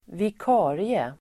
Uttal: [vik'a:rie]